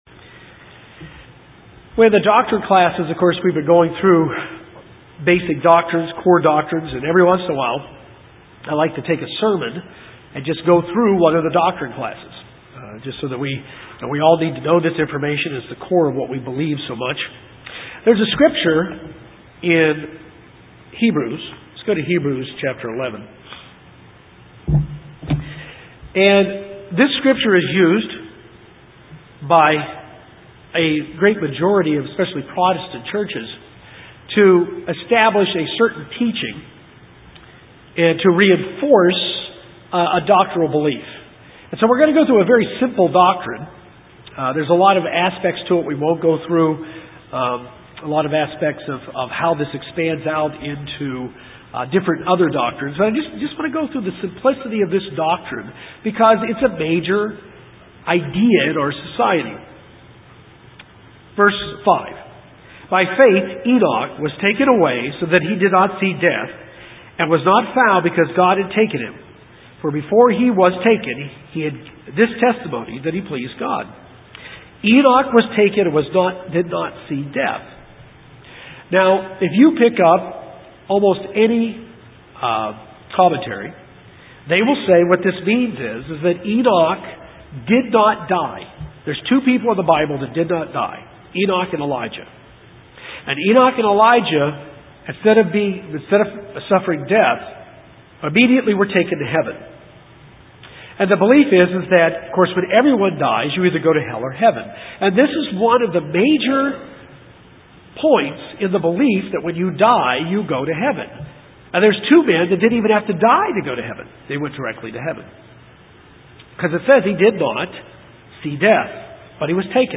This sermon covers the United Church of God’s belief about what happens to us when we die. It answers the questions about where heaven is and whether or not humans go to heaven when they die.